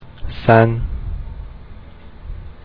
(Pronunciation)
mountain.rm